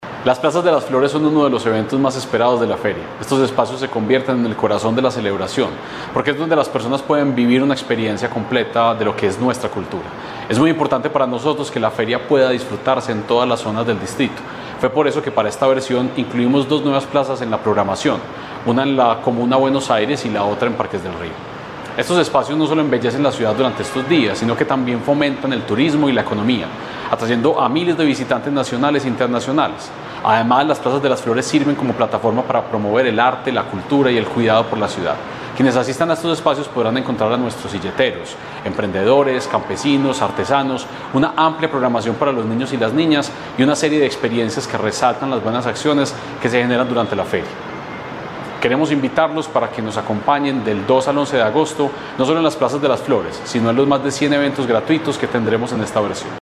Palabras de Santiago Silva, secretario de Cultura Ciudadana